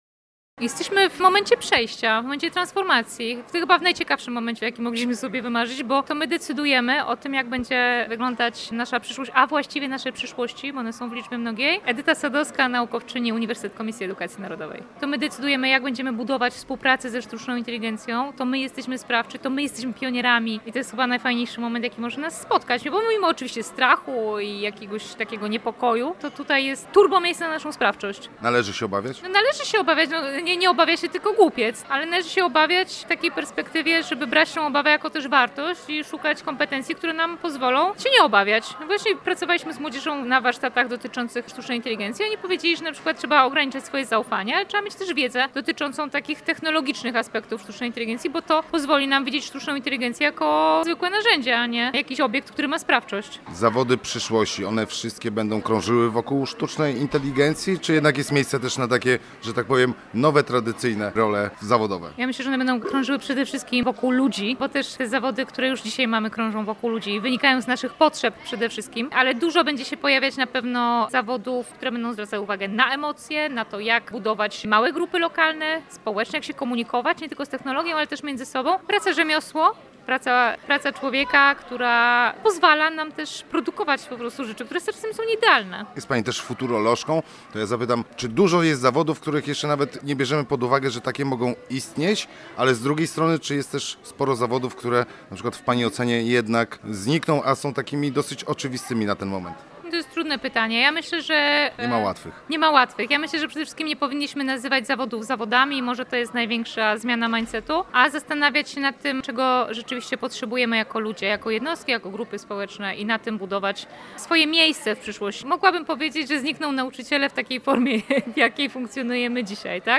Posłuchaj materiału naszego reportera o rozwoju sztucznej inteligencji i jej wpływie na rynek pracy: